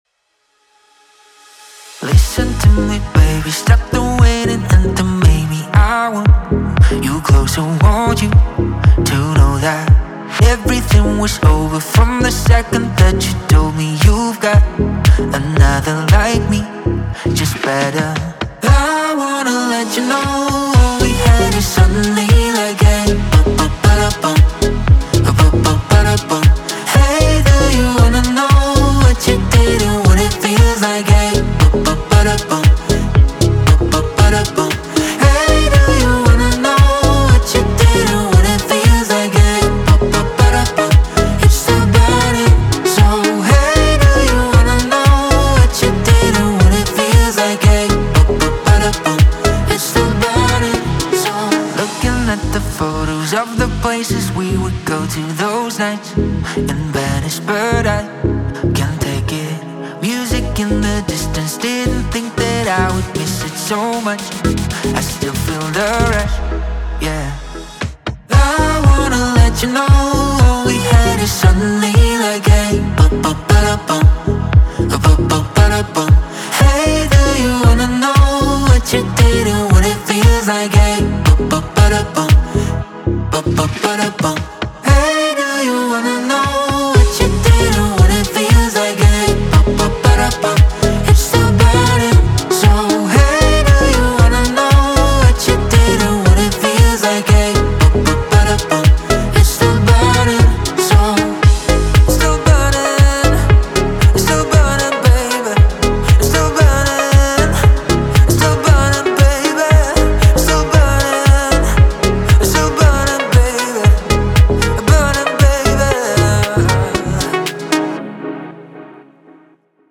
• Жанр: Electronic, Dance